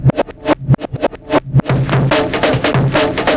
drums05.wav